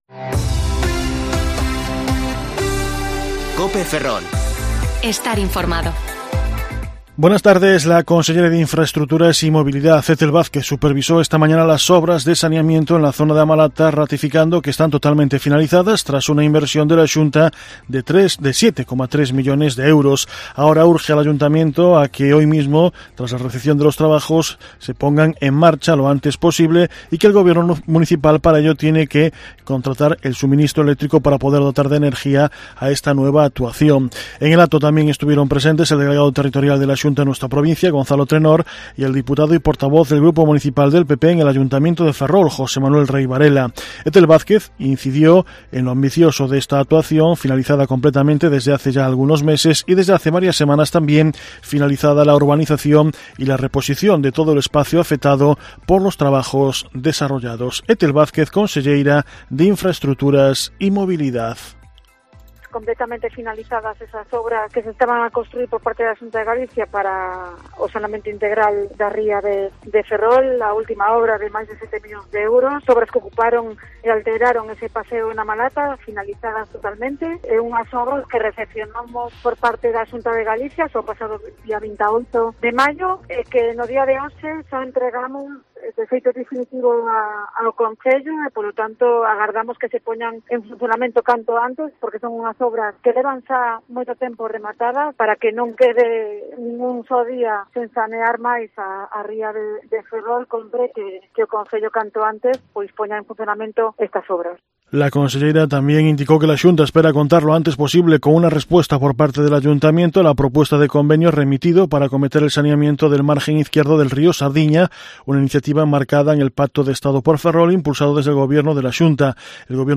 Informativo Mediodía COPE Ferrol 7/6/2021 (De 14,20 a 14,30 horas)